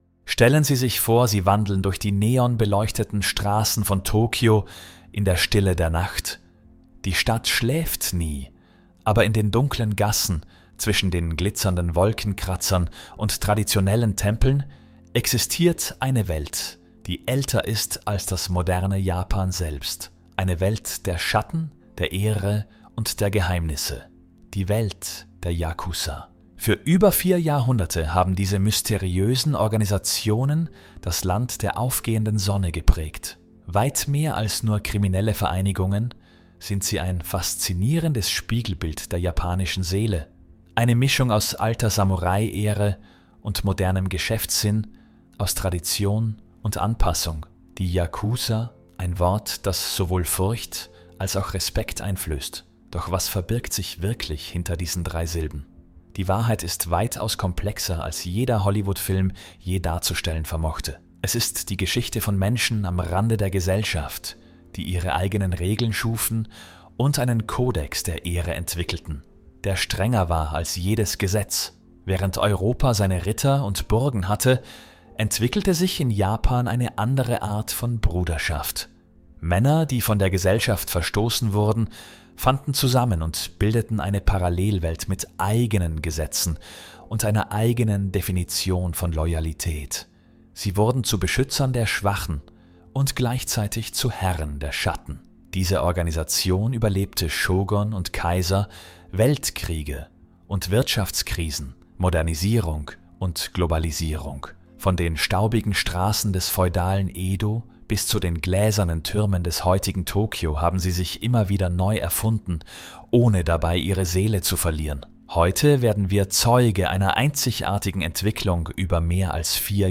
Die Ehrenmänner, die niemals ruhen | YAKUZA-Kodex als Einschlafmeditation